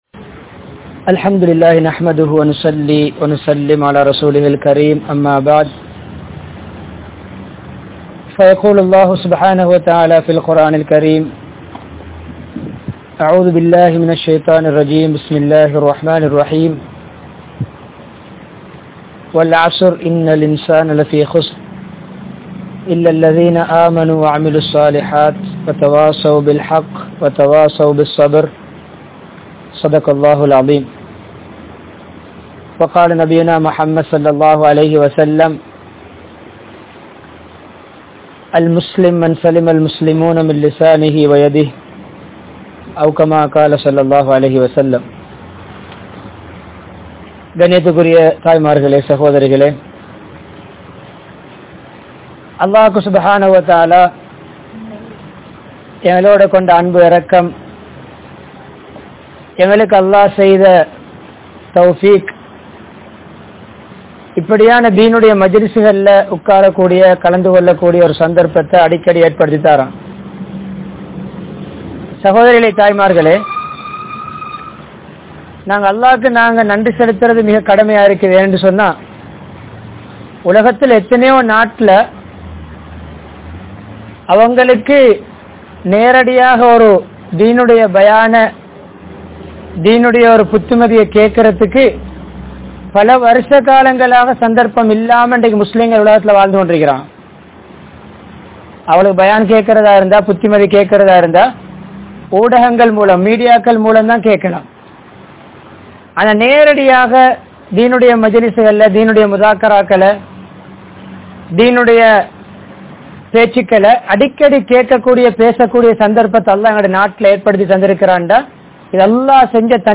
Thannai Thiruththum Murai (தன்னை திருத்தும் முறை) | Audio Bayans | All Ceylon Muslim Youth Community | Addalaichenai
Colombo, Kolannawa, Megoda, Faluliyyah Masjidh